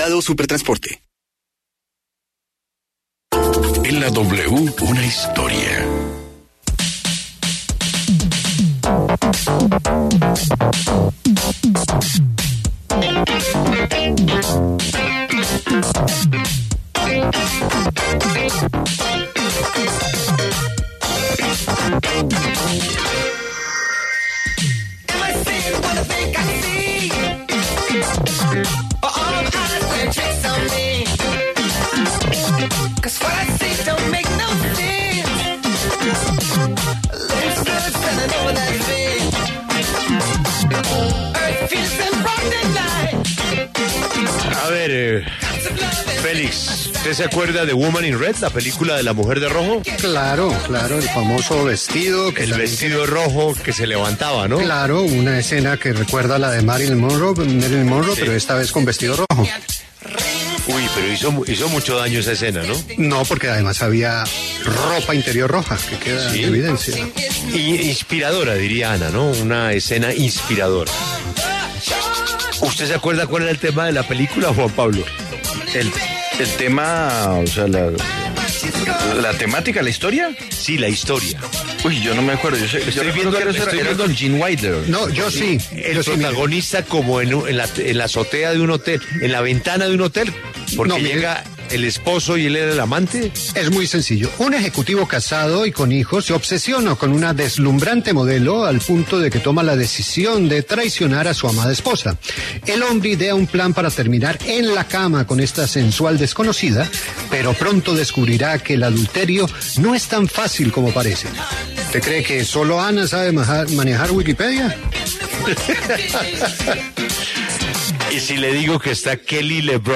Kelly LeBrock, ícono del cine de la década de los años 80, habló en La W sobre su decisión de dejar el mundo del cine para luego regresar en la película “Tomorrow’s Today”.